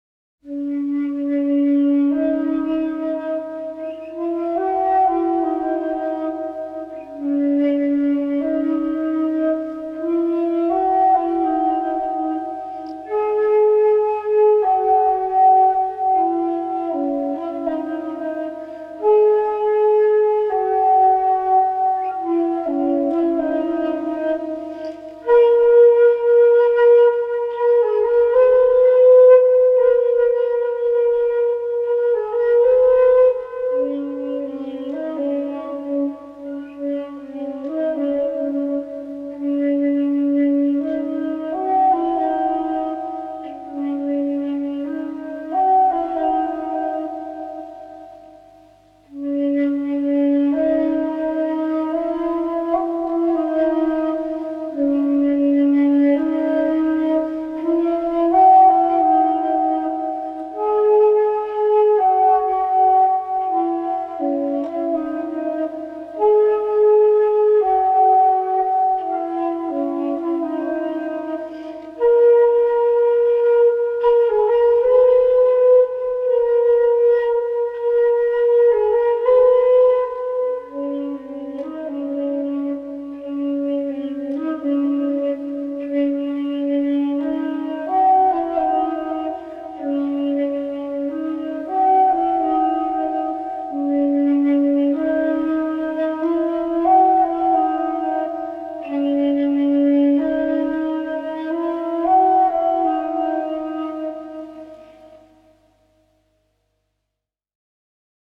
Flute Music Meditation